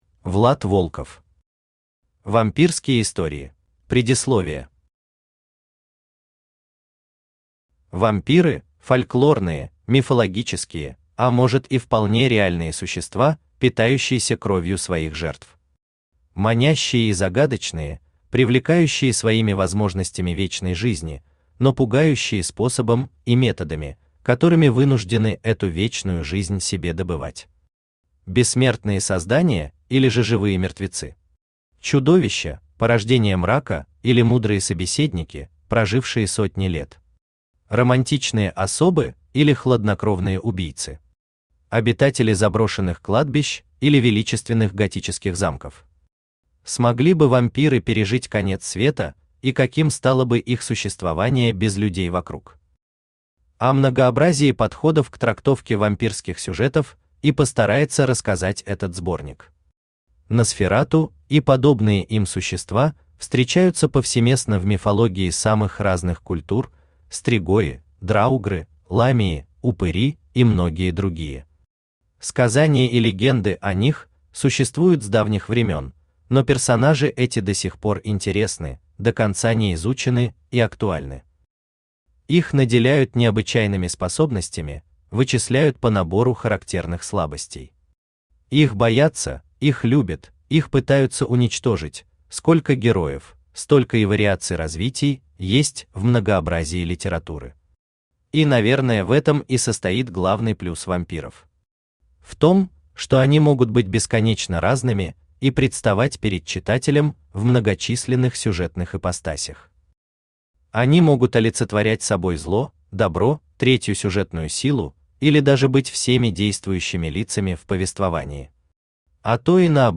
Аудиокнига Вампирские истории | Библиотека аудиокниг
Aудиокнига Вампирские истории Автор Влад Волков Читает аудиокнигу Авточтец ЛитРес.